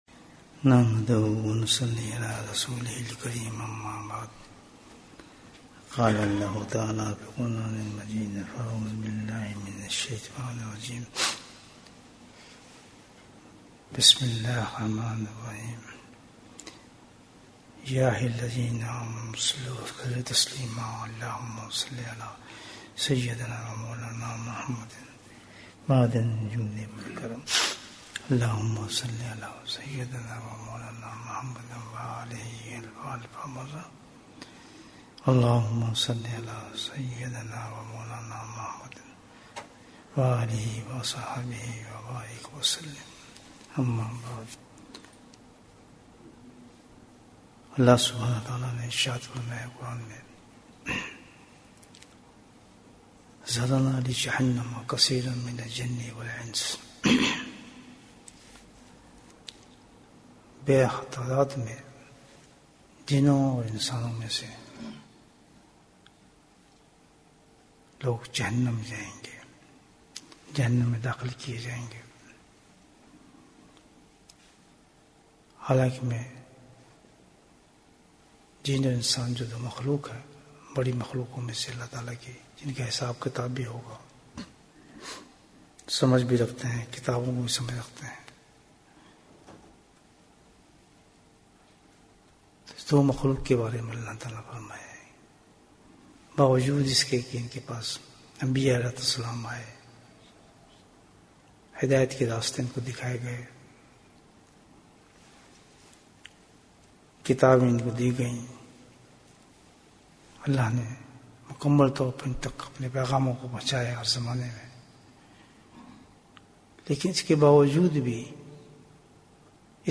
Bayan, 50 minutes3rd February, 2020